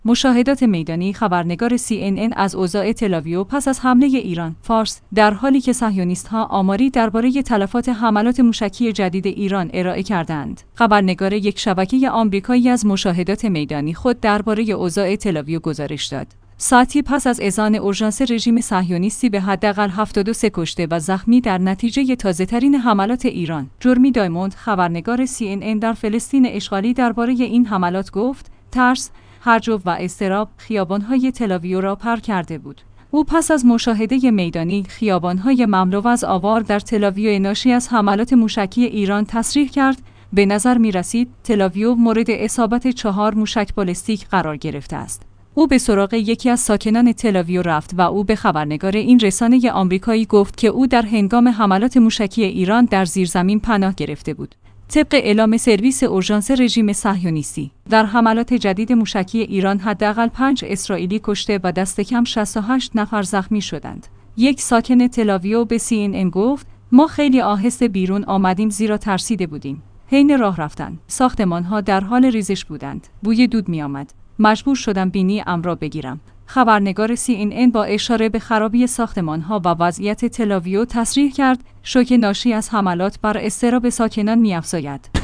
مشاهدات میدانی خبرنگار سی‌ان‌ان از اوضاع تل‌آویو پس از حمله ایران